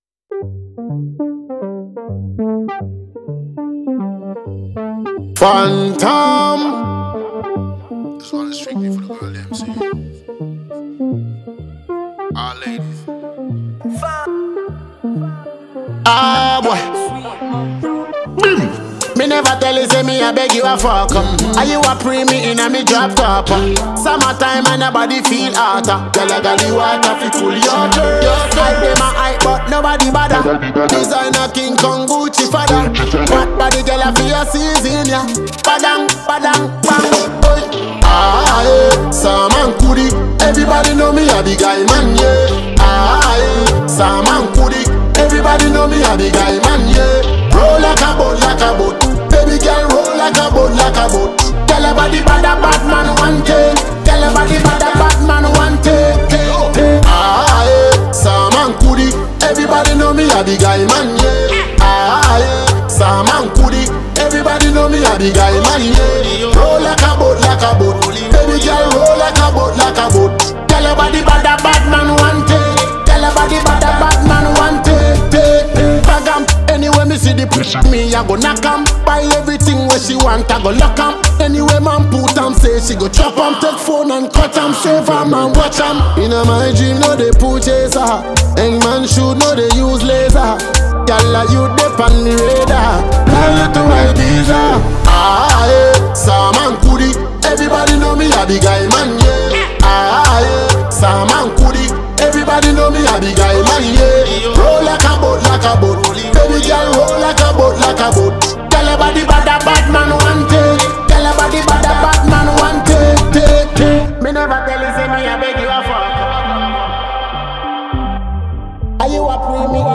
Ghanaian Afro-dancehall musician